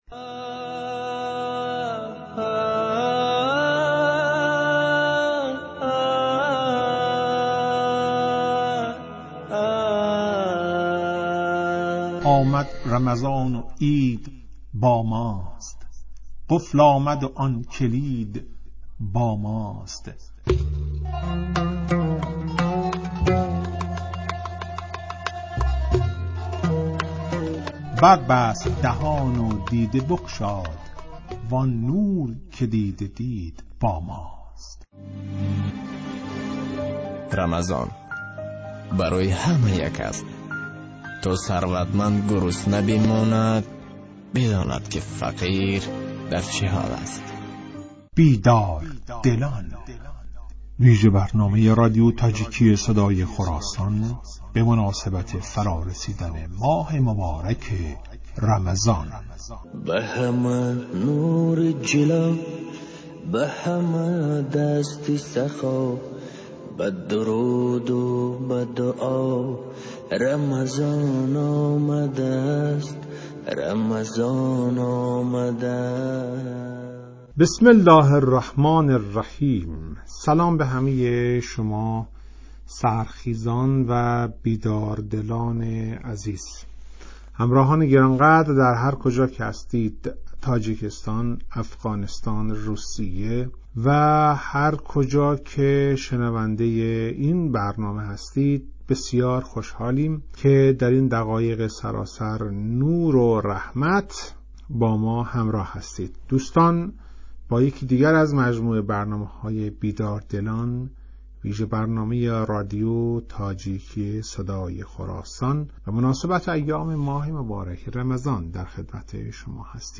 "بیدار دلان" ویژه برنامه ای است که به مناسبت ایام ماه مبارک رمضان در رادیو تاجیکی تهیه و پخش می شود.